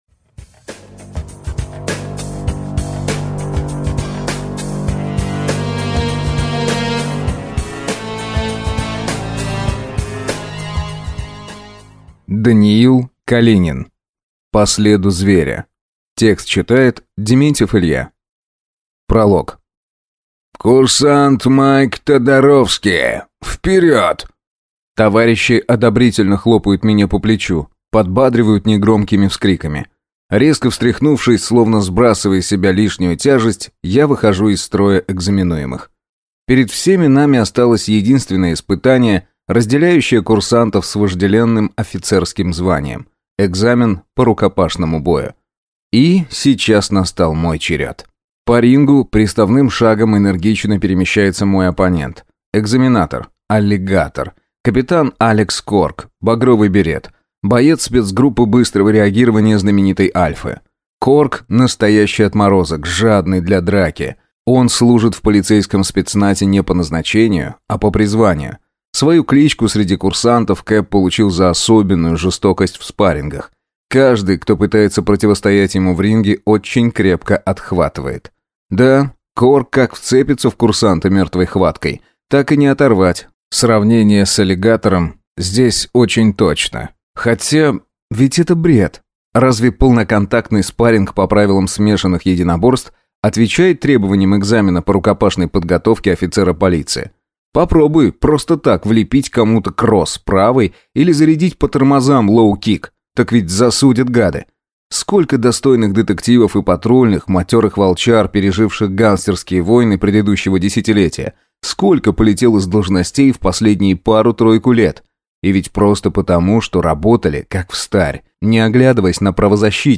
Аудиокнига По следу зверя | Библиотека аудиокниг